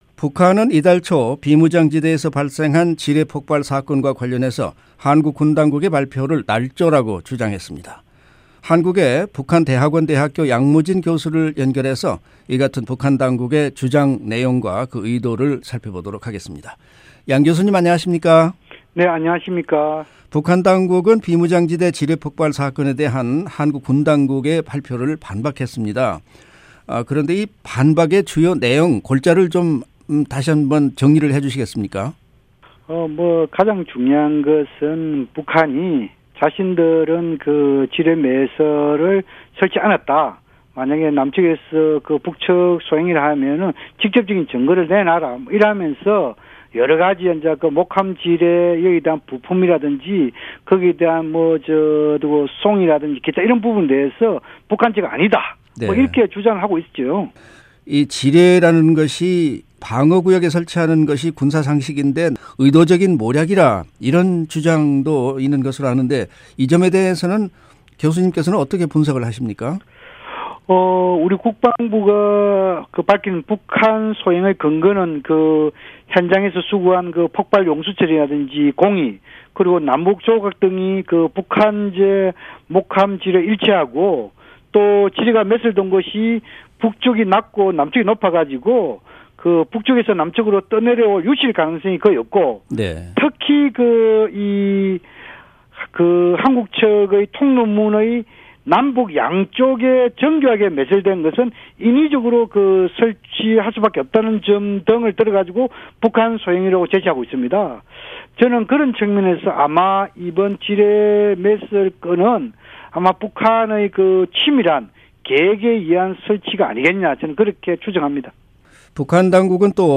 [인터뷰: